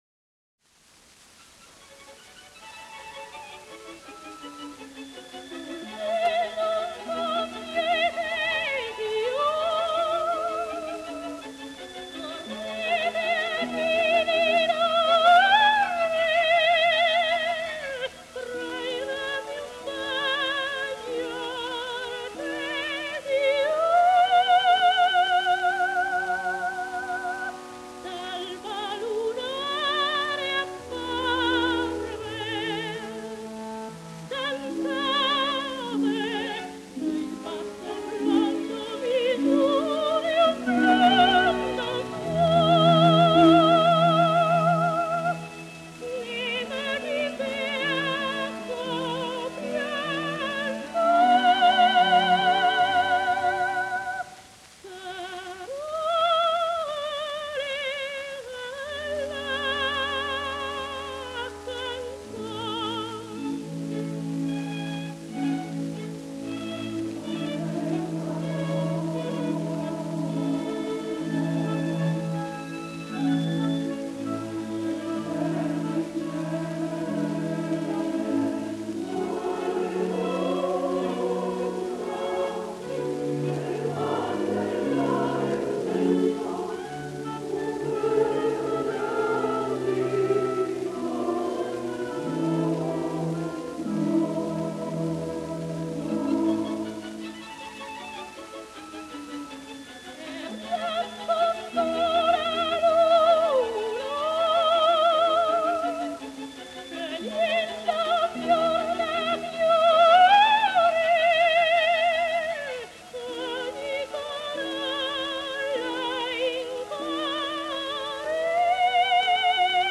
ДАЛЬ MОHTE (Dal Monte) Тоти (наст. имя и фамилия - Антониетта Mенегелли, Meneghelli) (27 VI 1893, Мольяно-Венето - 26 I 1975) - итал. певица (колоратурное сопрано).
Редкий по красоте тембра, гибкости и яркости звучания голос, виртуозное вок. мастерство, музыкальность и артистич. дарование поставили Д. М. в ряд выдающихся оперных певиц мира.